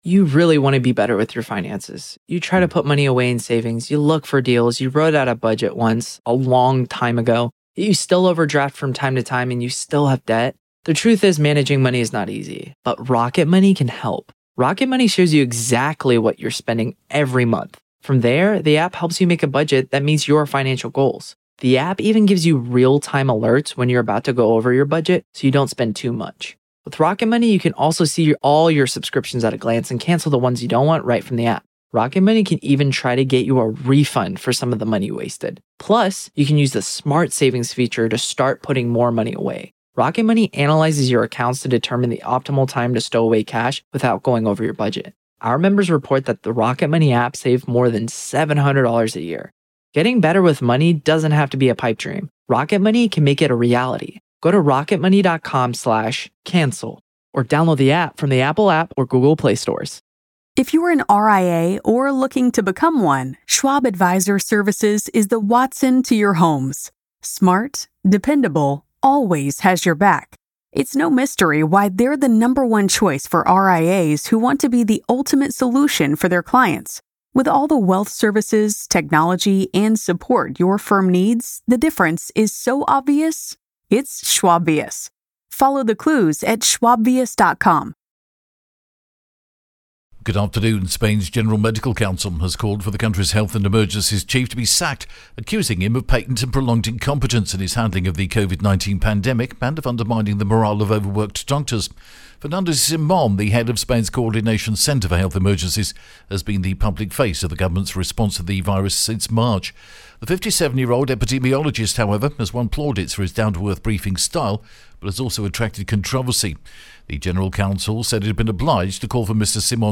The latest Spanish news headlines in English: November 16th